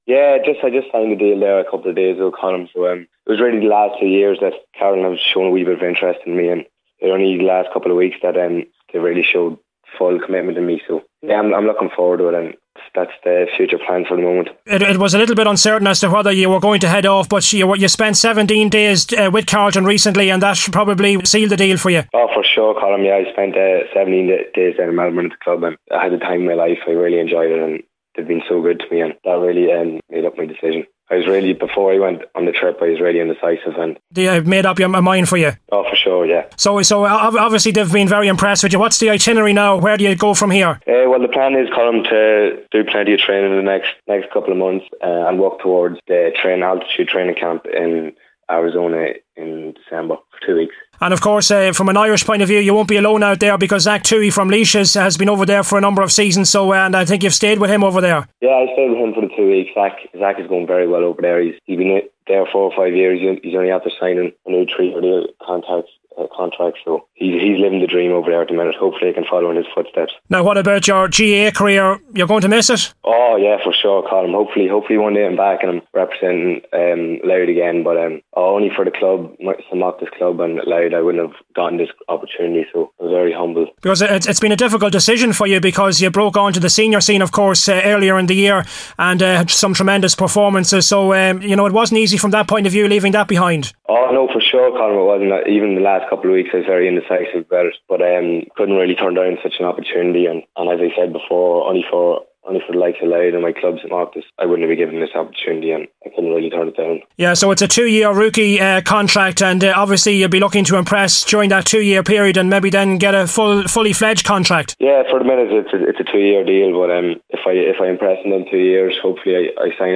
speaking on LMFM Sport about his imminent move to Carlton in Australia to play Aussie Rules